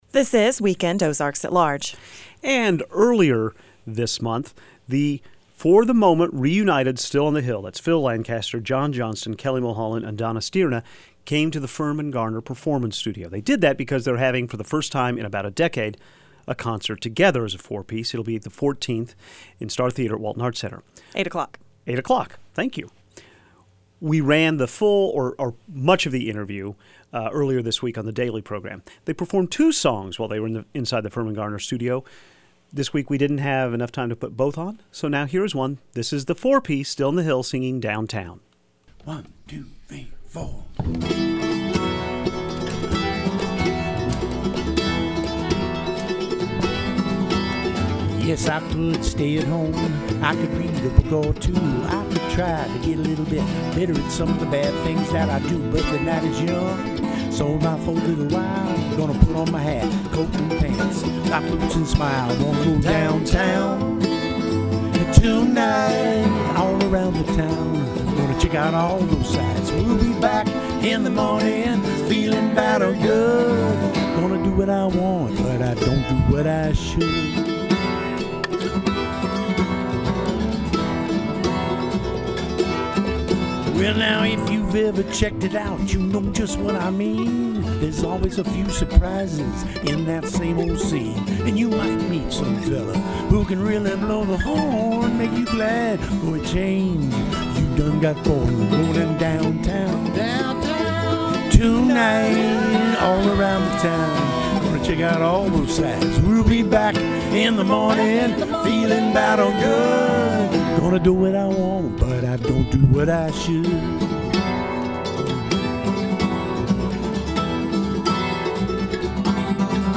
the reunited four-piece